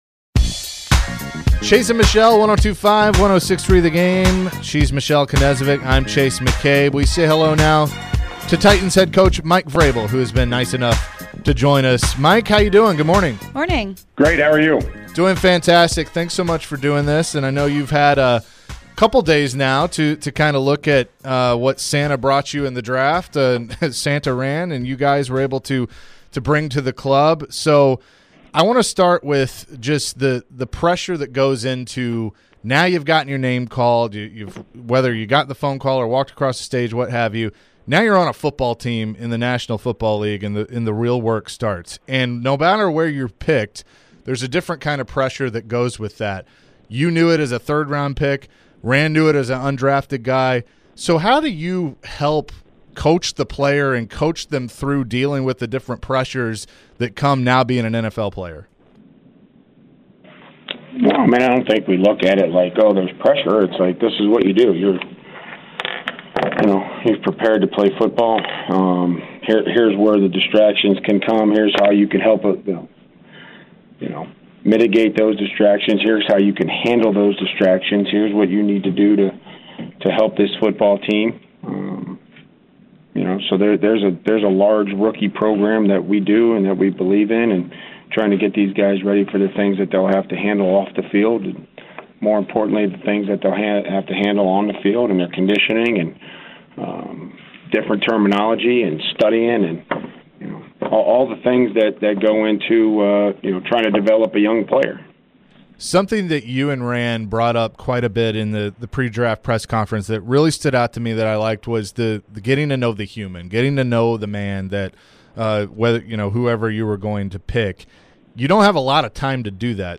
Mike Vrabel Interview (5-2-23)